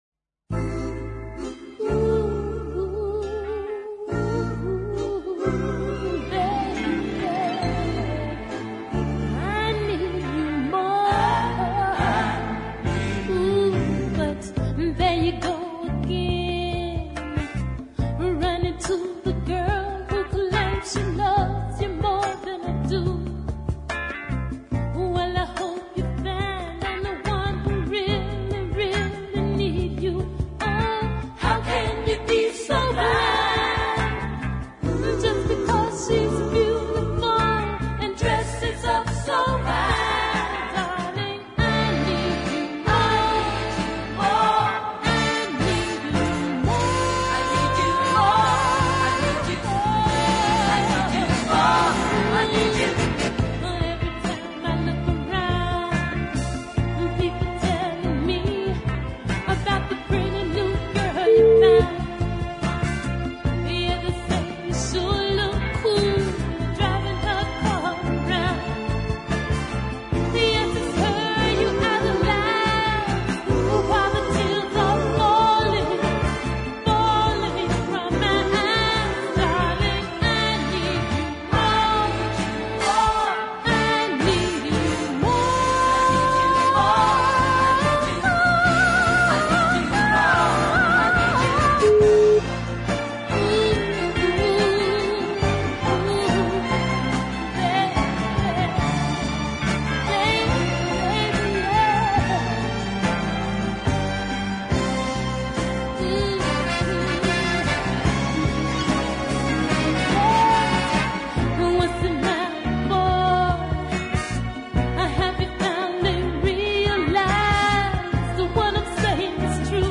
This superbly melodic number
lovely guitar licks